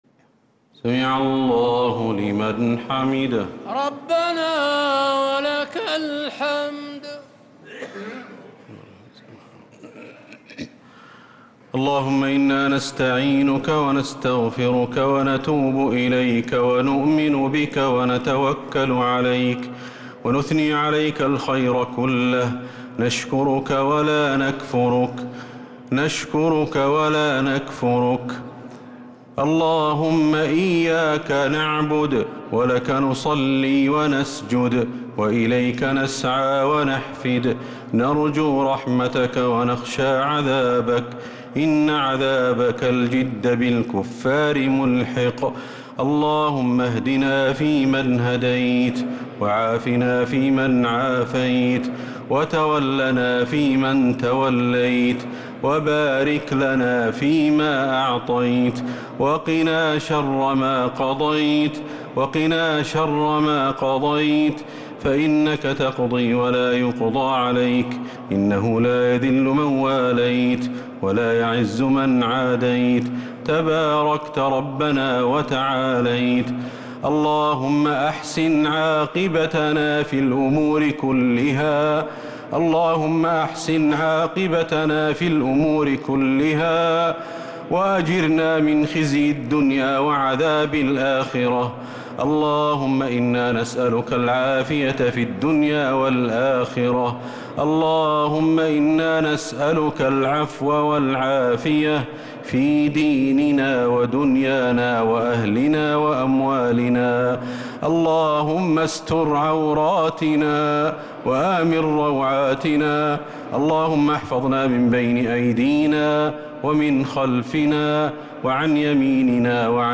دعاء القنوت ليلة 26 رمضان 1446هـ | Dua 26th night Ramadan 1446H > تراويح الحرم النبوي عام 1446 🕌 > التراويح - تلاوات الحرمين